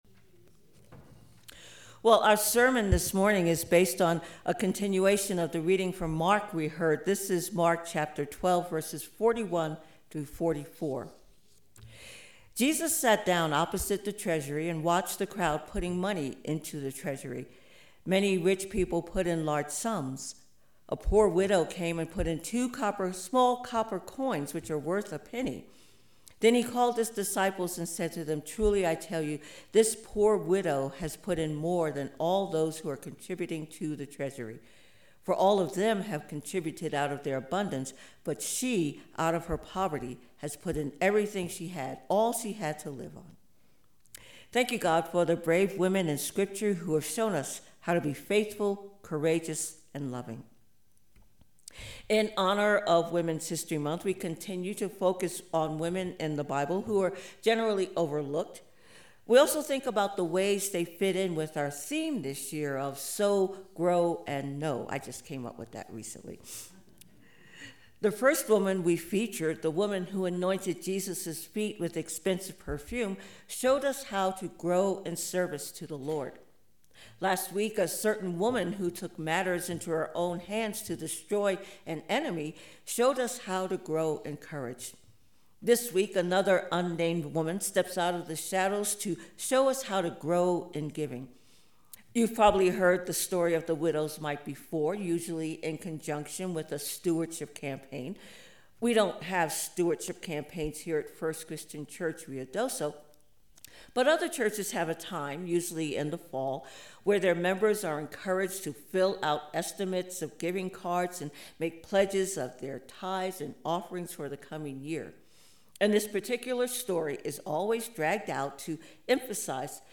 Sermon text: Mark 12: 41-44
Special Music: “Whispering Hope” (Alice Hawthorne) FCC Choir